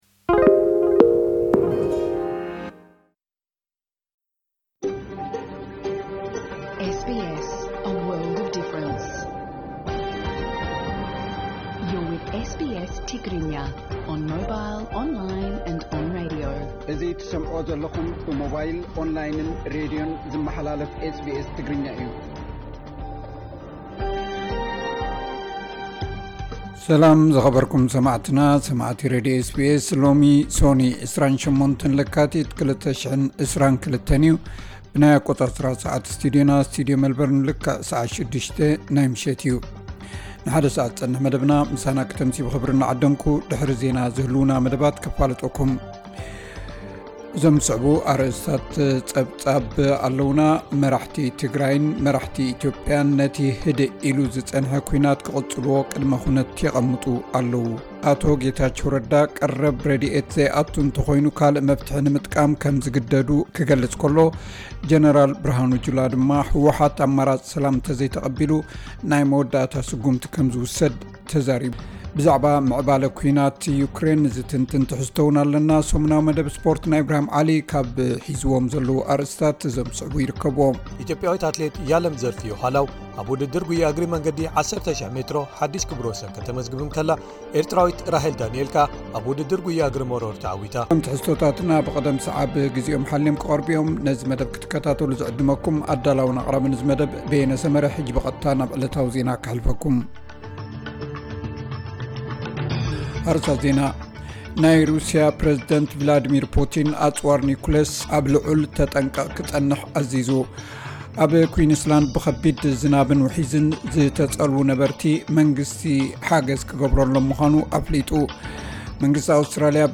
ቀጥታ ፈነወ SBS ትግርኛ 28 ለካቲት 2022